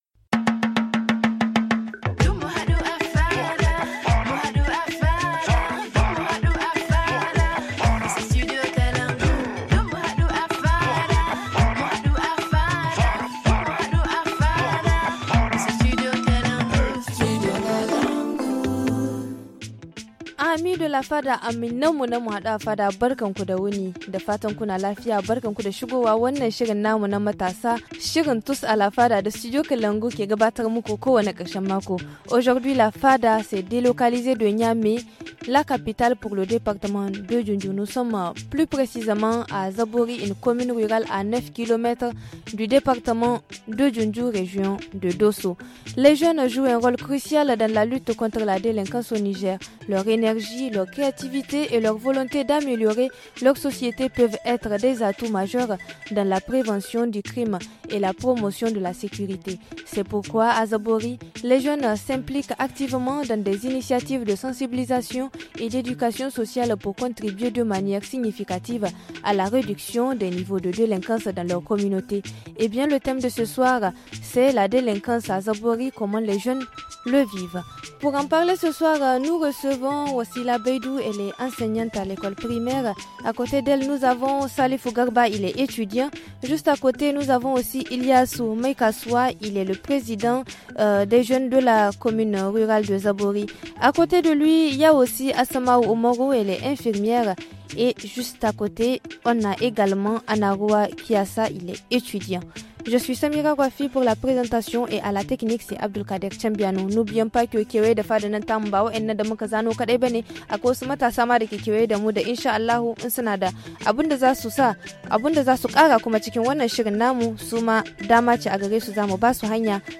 La Fada des jeunes du Studio Kalangou s’est rendue à la rencontre des jeunes de la commune rurale de Zabori, dans le département de Dioundiou, région de Dosso.